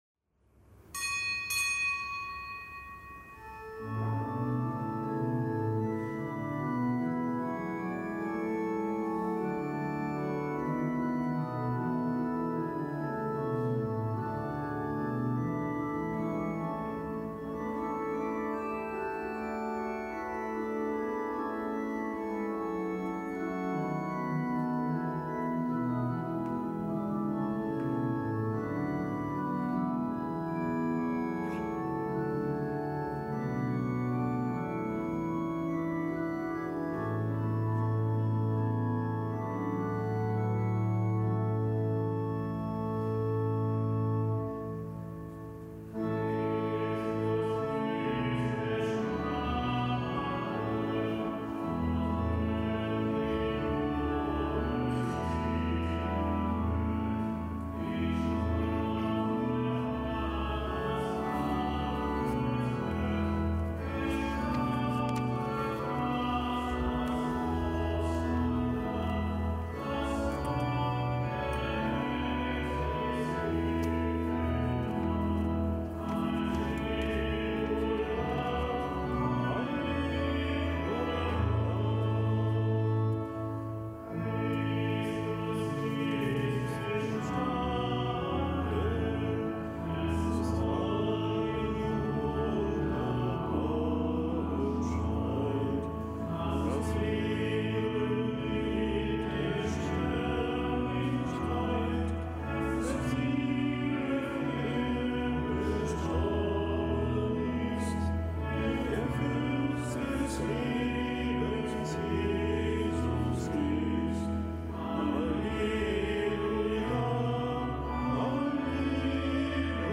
Kapitelsmesse aus dem Kölner Dom am Donnerstag der Osteroktav